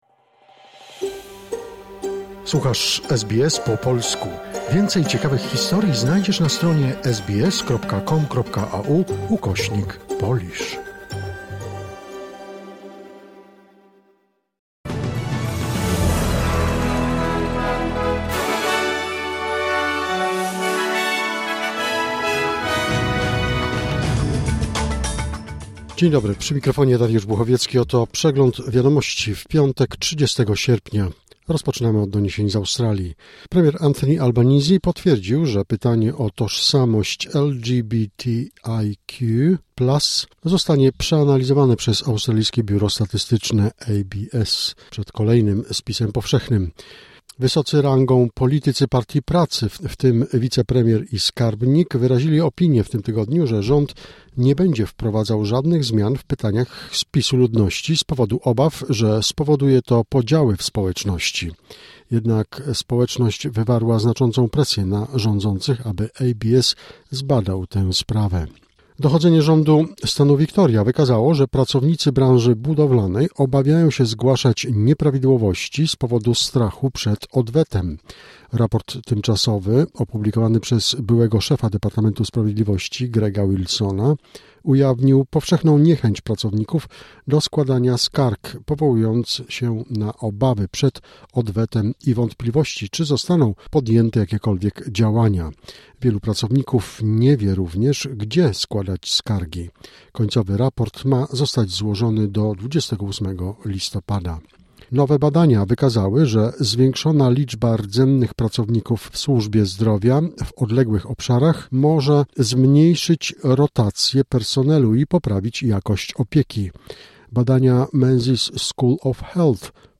Wiadomości 30 sierpnia SBS News Flash